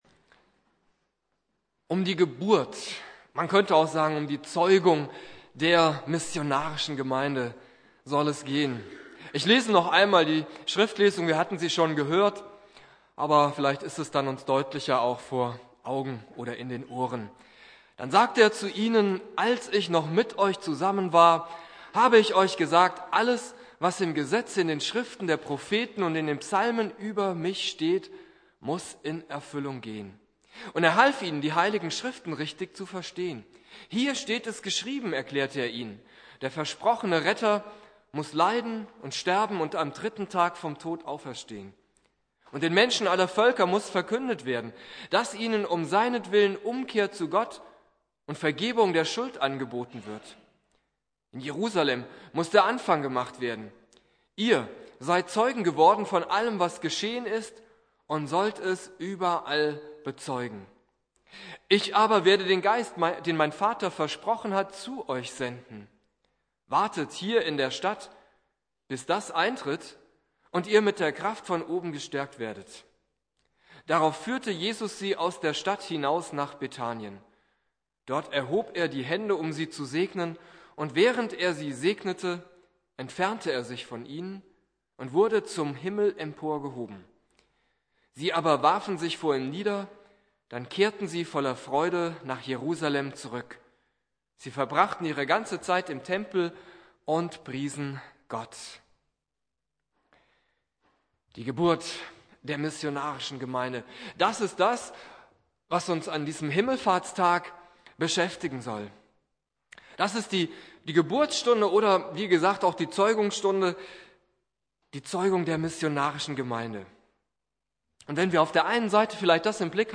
Predigt
Christi Himmelfahrt